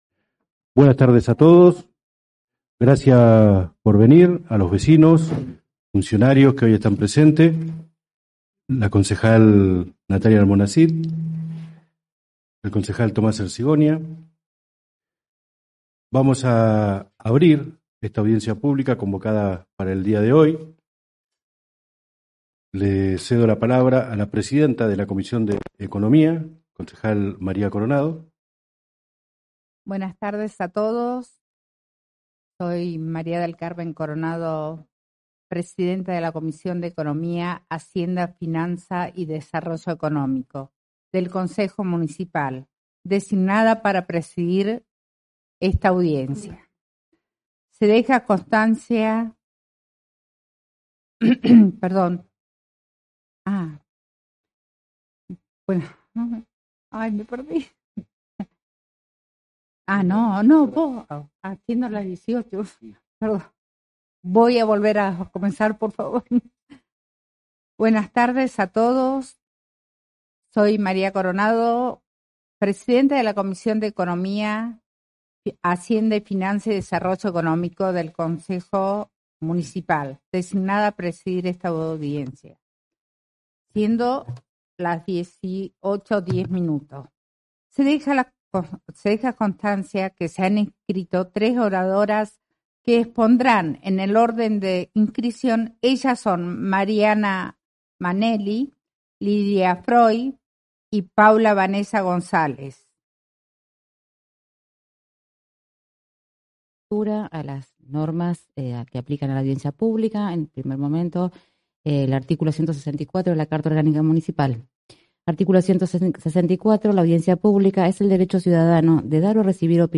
Lugar: Sala de Sesiones del Concejo Municipal.